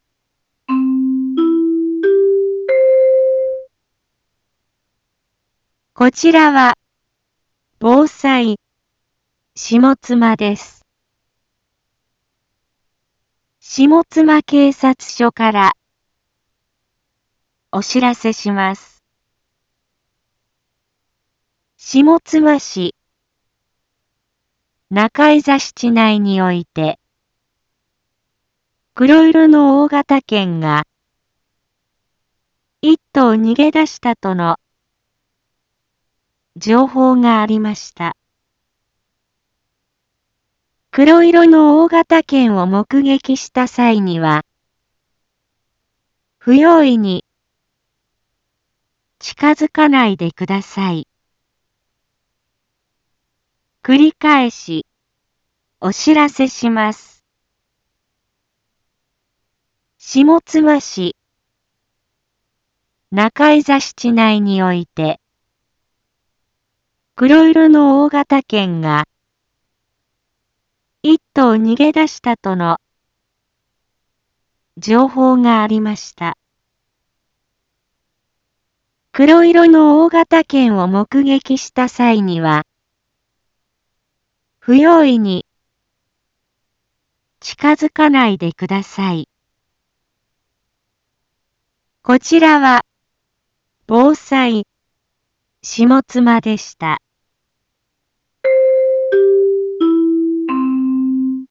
一般放送情報
Back Home 一般放送情報 音声放送 再生 一般放送情報 登録日時：2025-03-26 07:06:01 タイトル：大型犬の徘徊情報について インフォメーション：こちらは防災下妻です。